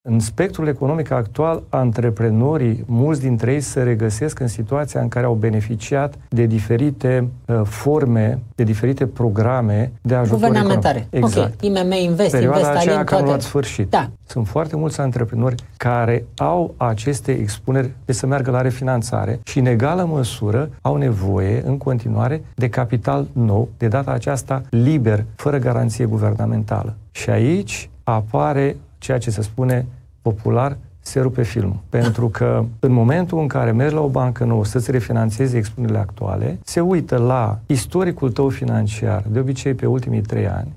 Situația a fost analizată într-o dezbatere cu tema „Cum pot fi relansate investițiile în România?”, la „Ziarul Financiar”.